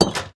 Minecraft Version Minecraft Version latest Latest Release | Latest Snapshot latest / assets / minecraft / sounds / block / iron_door / close1.ogg Compare With Compare With Latest Release | Latest Snapshot